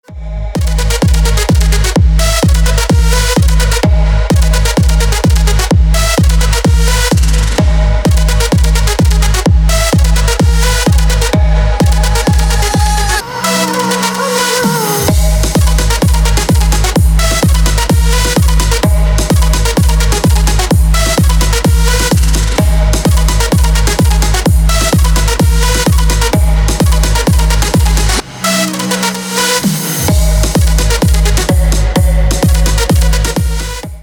• Песня: Рингтон, нарезка
Клубный рингтон 2024 на звонок